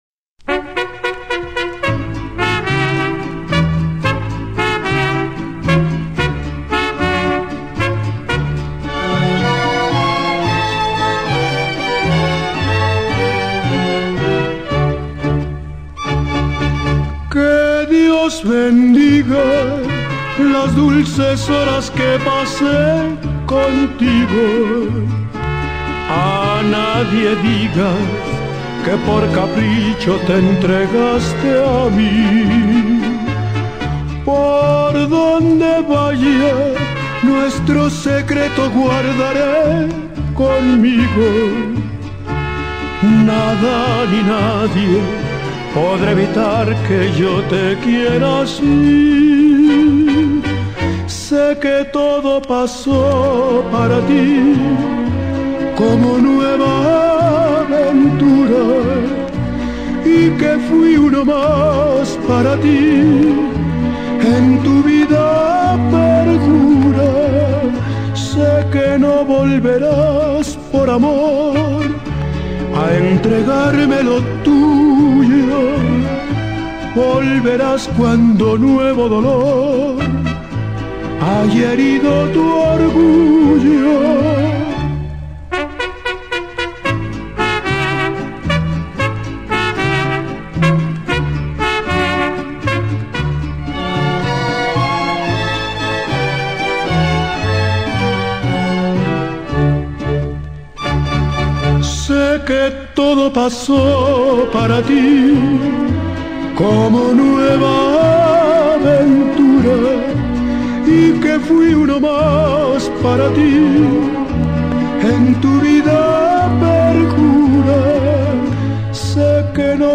el vinilo es hasta ahora la única fuente.